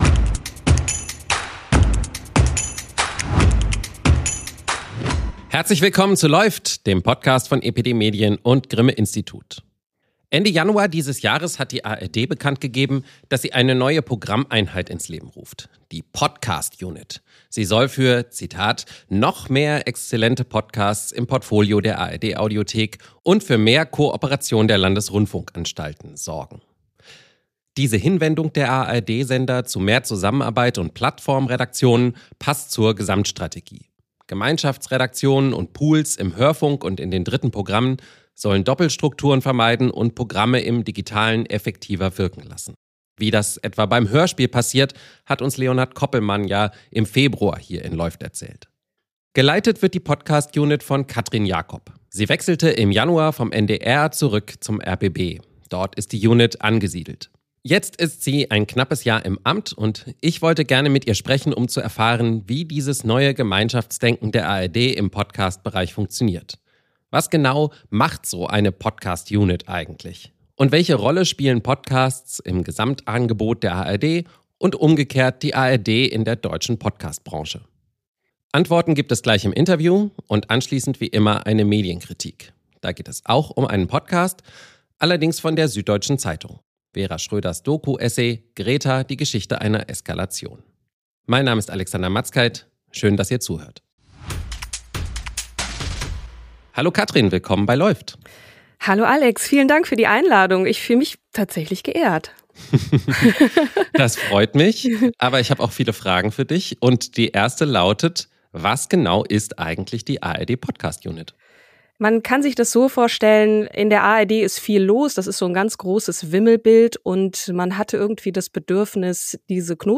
Die Podcast-Unit der ARD soll für mehr Zusammenarbeit zwischen den Landessendern und höhere Qualität von ARD-Podcasts sorgen. Im Interview